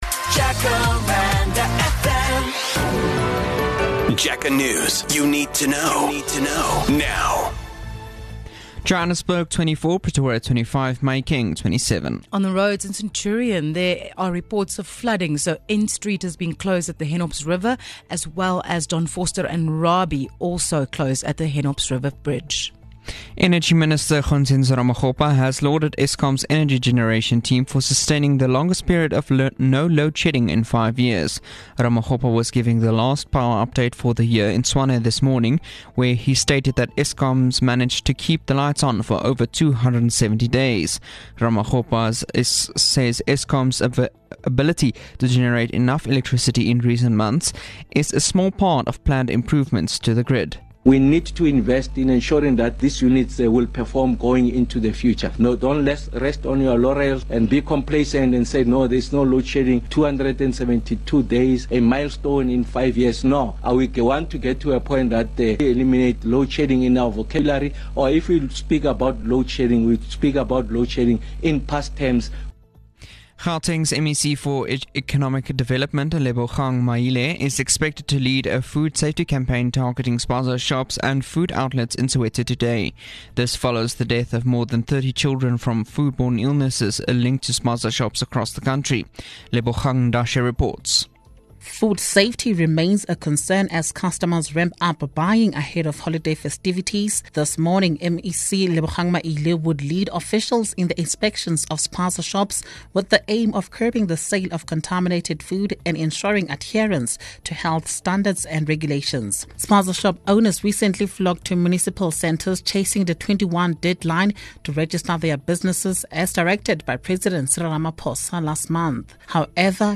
JacarandaFM News @ 08H03.